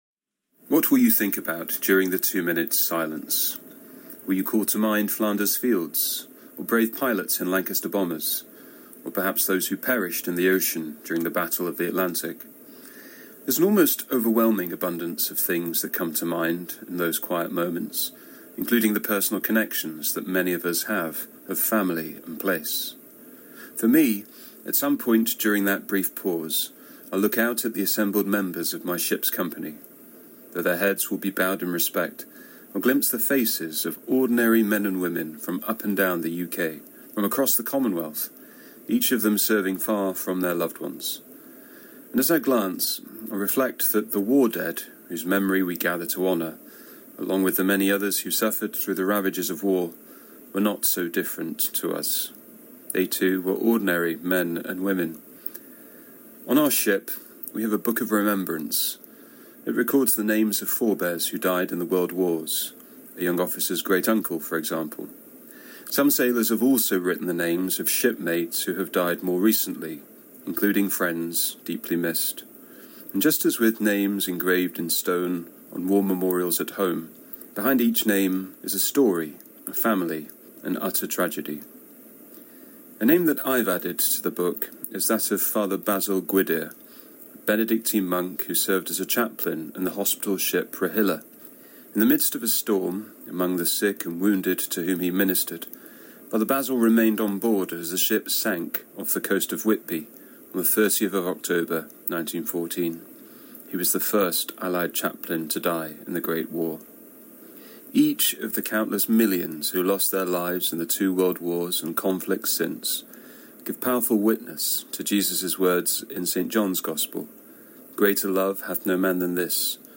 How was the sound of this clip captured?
He gave a poignant reflection on remembrance and honouring our war dead on BBC Radio 4's Thought for the Day. It was broadcast on 11 November, Armistice Day, known as Remembrance Day in the Commonwealth.